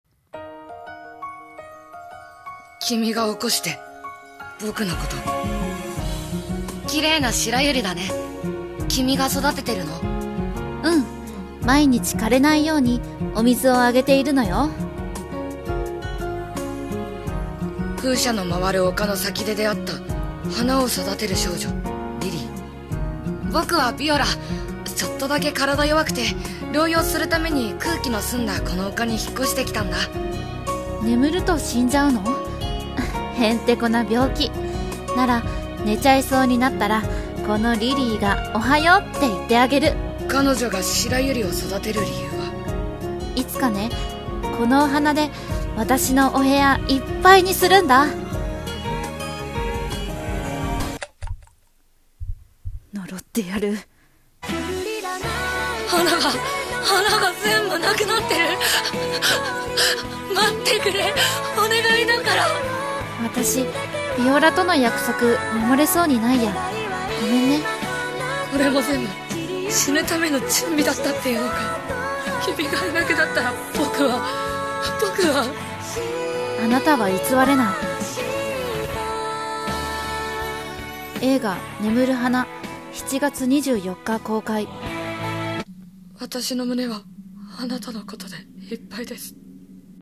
CM風声劇「眠る花」